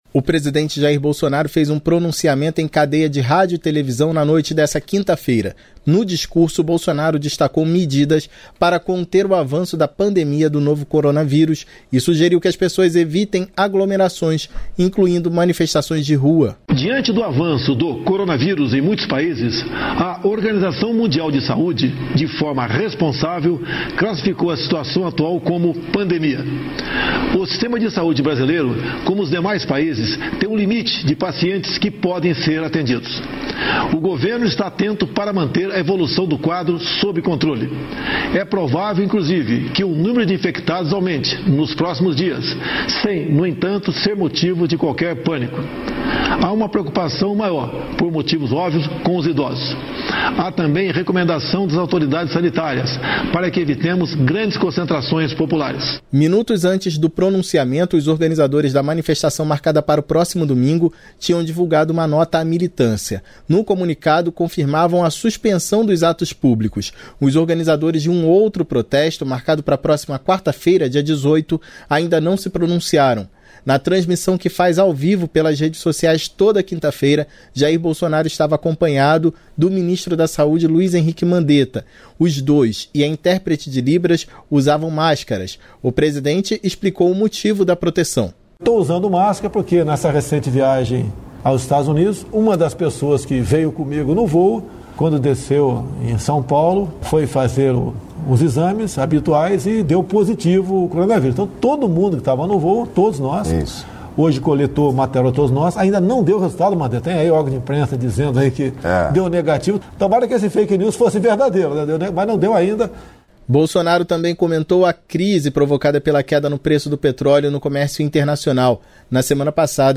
Em pronunciamento, Bolsonaro recomenda evitar aglomeração, incluindo manifestações